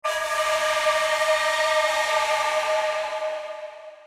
Scary Game Effect Sound Button - Free Download & Play